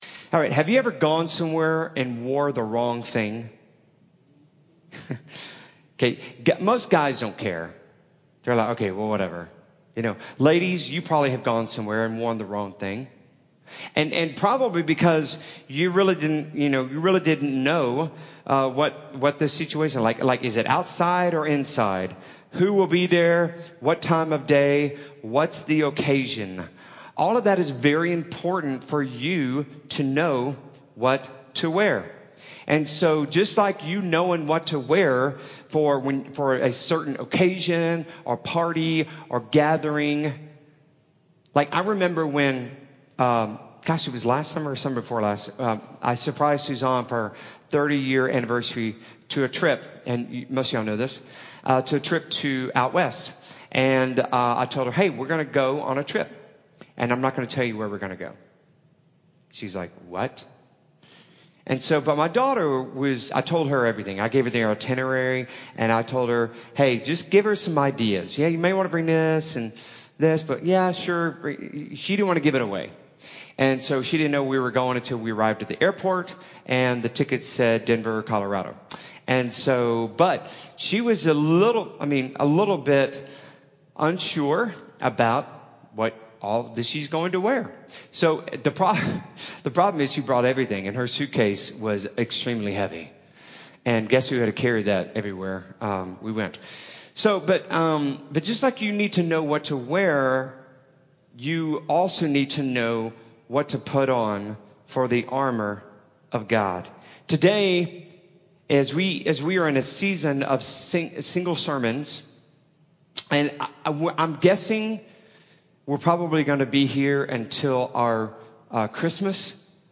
Weapons-of-Our-Warfare-Sermon-CD.mp3